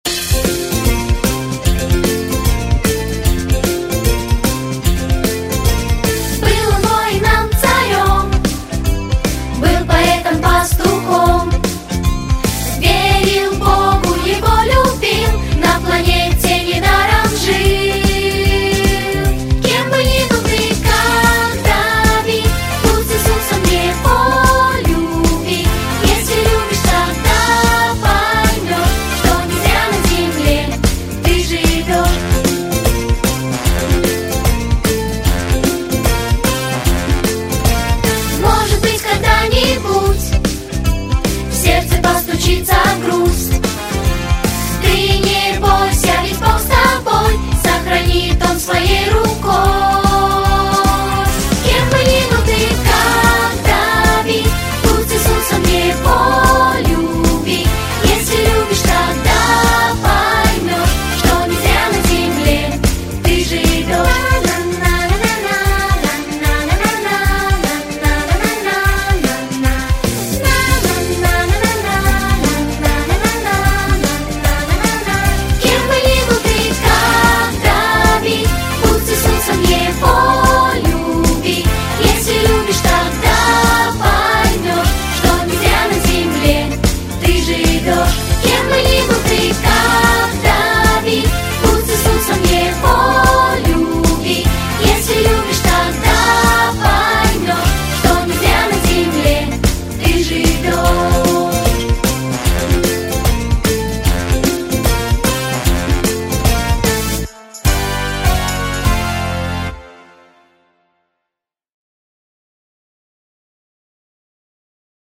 • Категория: Детские песни
🎶 Детские песни / Песни на праздник / Христианские Песни ⛪